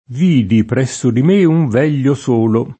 veglio [v$l’l’o] agg. e s. m.; pl. m. vegli — arcaismo per «vecchio»: Vidi presso di me un veglio solo [